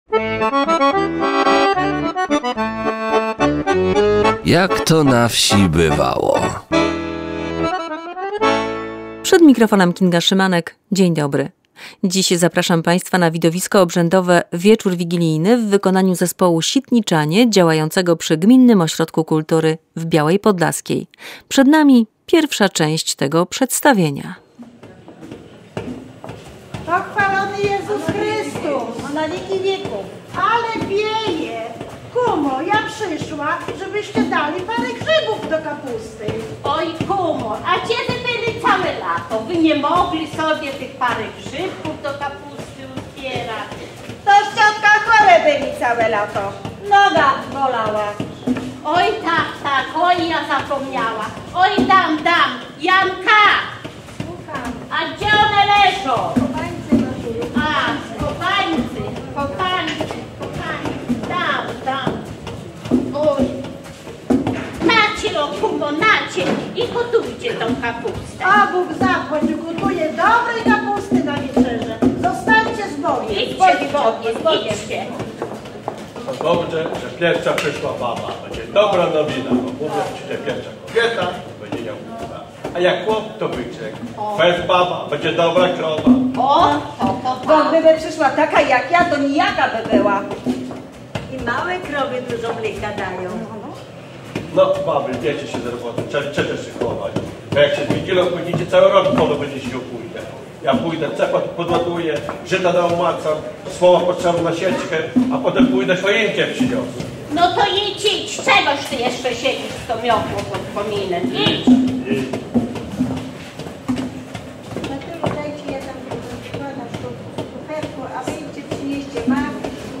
Widowisko obrzędowe "Wieczór wigilijny" w wykonaniu zespołu Sitniczanie cz. 1.